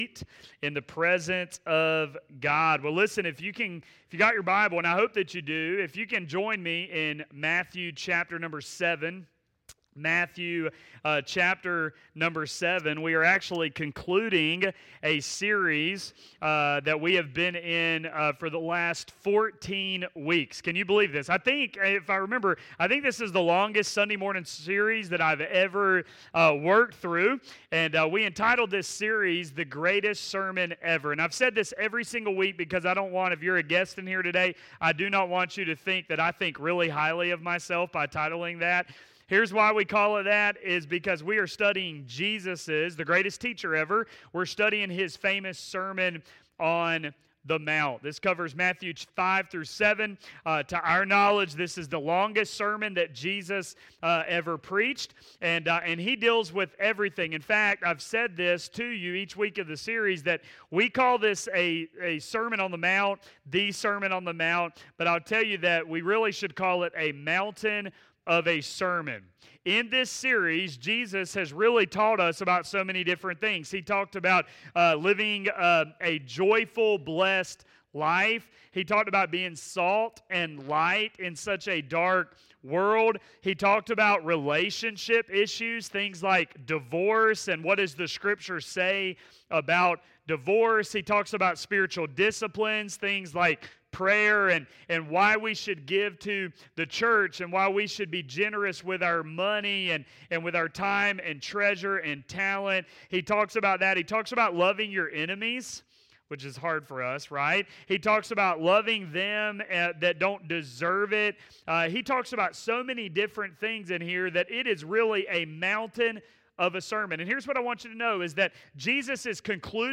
The Greatest Sermon Ever